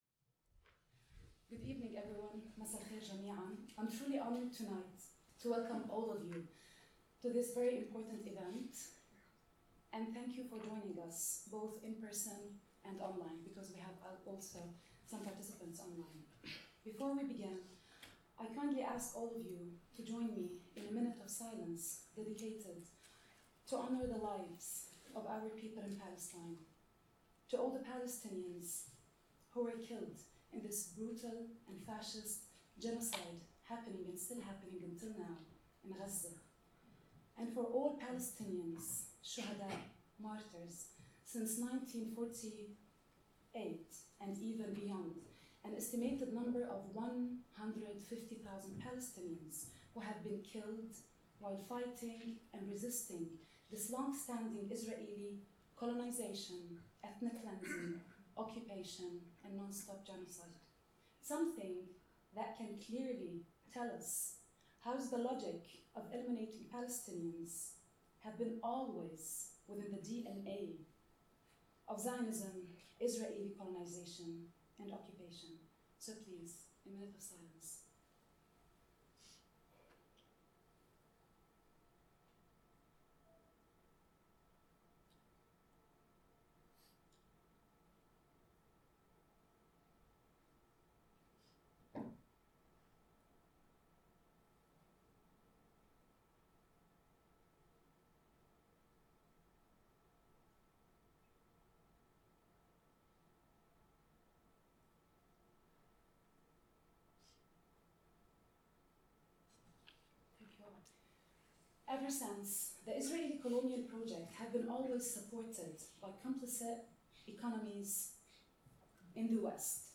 This seminar was delivered at the Middle East Centre on 30 May 2025.